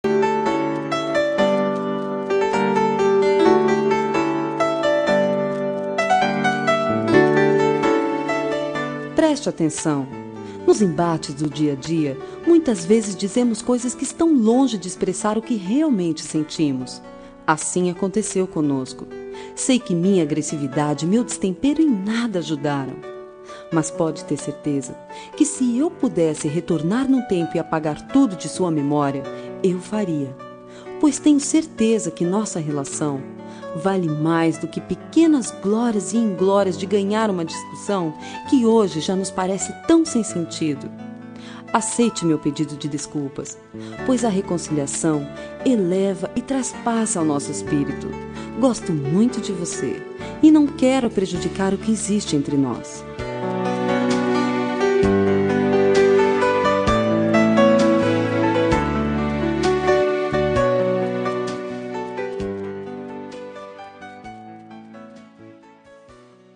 Reconciliação Amizade – Voz Feminina – Cód: 036797